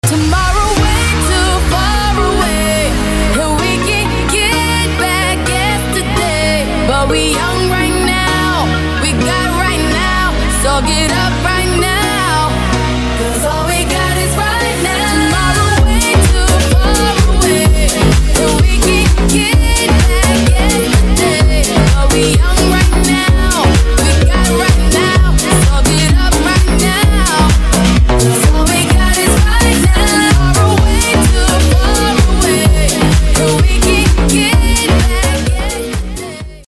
• Качество: 256, Stereo
dance
house